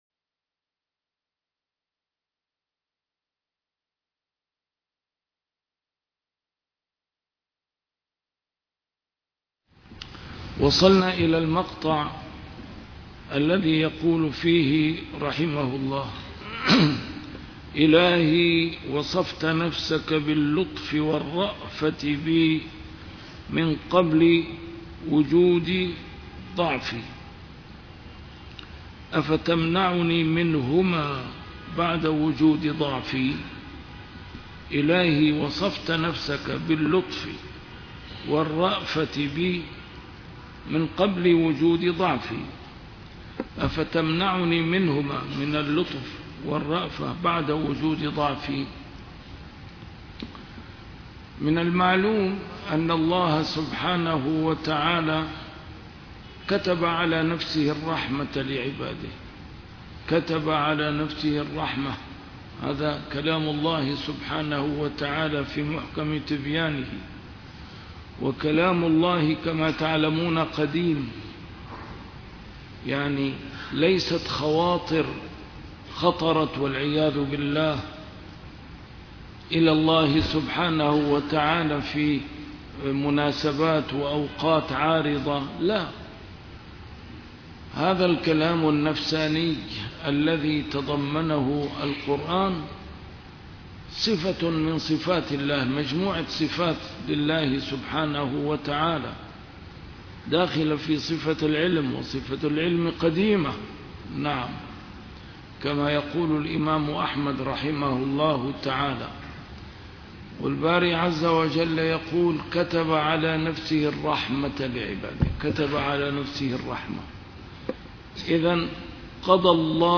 A MARTYR SCHOLAR: IMAM MUHAMMAD SAEED RAMADAN AL-BOUTI - الدروس العلمية - شرح الحكم العطائية - الدرس رقم 294 شرح الحكمة رقم 263